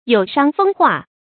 有傷風化 注音： ㄧㄡˇ ㄕㄤ ㄈㄥ ㄏㄨㄚˋ 讀音讀法： 意思解釋： 指對社會風俗、教育有不好影響的言行（多指男女關系方面）。